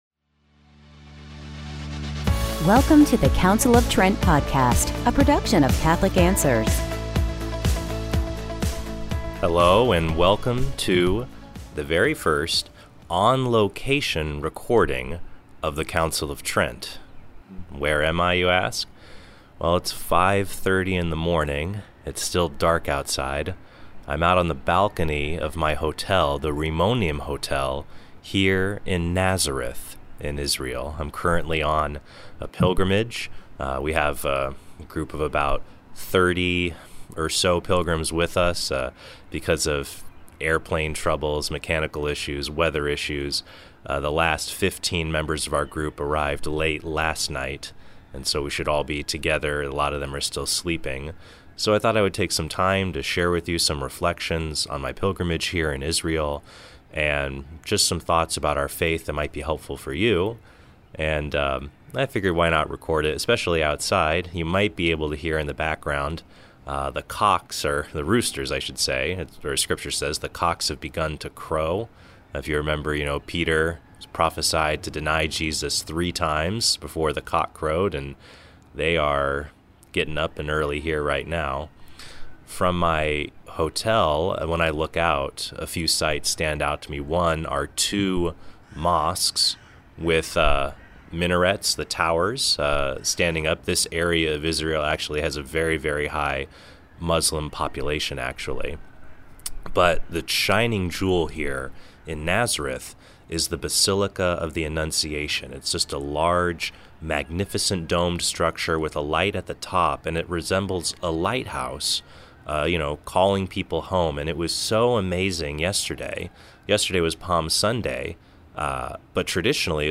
from the balcony of his hotel room in Nazareth